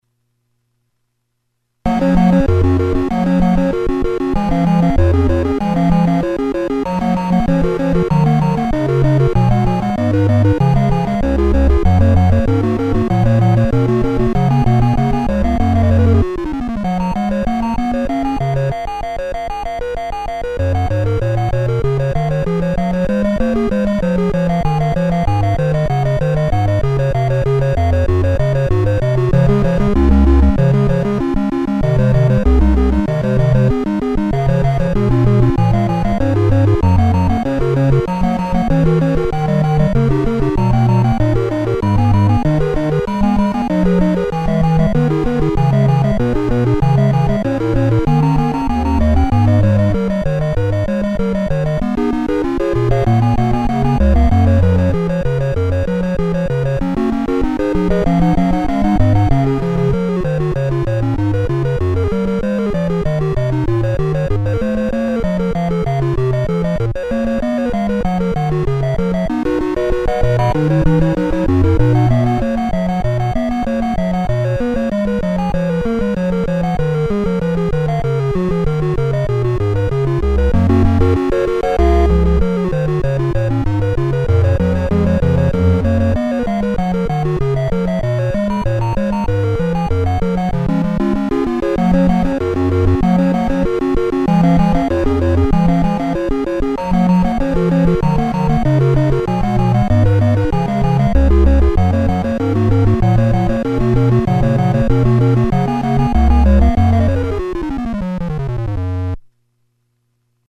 and because the sound of an organ could be reasonably convincingly emulated using the means at his disposal.
"If you’re generous, it sounds like a pipe organ," he reflected.
The flip-flops were connected to an electronic filter, amplifier, and loudspeaker, which removed some noise components of the signals and rendered them readily audible as musical notes.
Below is an audio clip of the third movement, "Presto," from J.S. Bach's Organ Concerto in G Major on PDP-1.